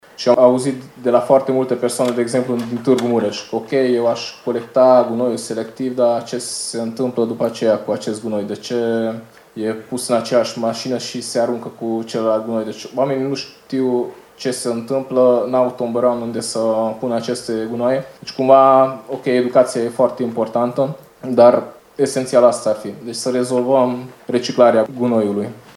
În acest sens, Radio Tg.Mureş a iniţiat şi organizat miercuri, 15 noiembrie, o dezbatere la care au participat autoritățile publice locale și județen,  dar și reprezentanţi ai ONG-urilor de profil.